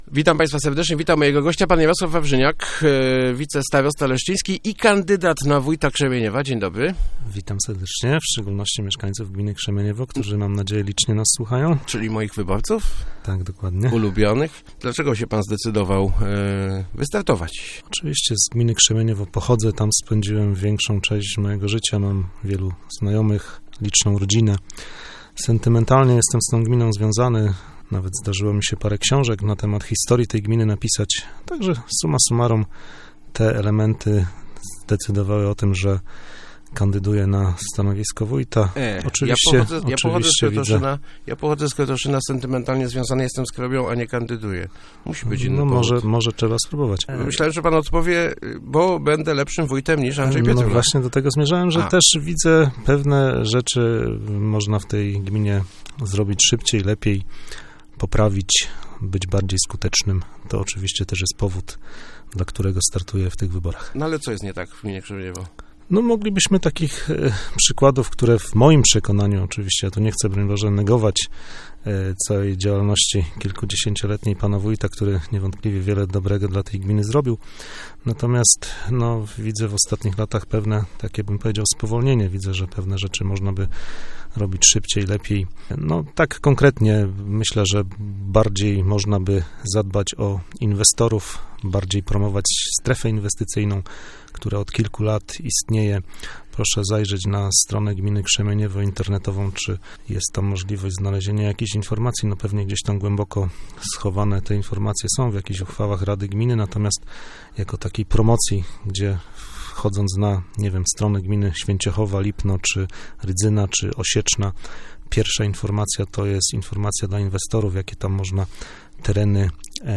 Musimy jak najpilniej znaleźć inwestorów - mówił w Rozmowach Elki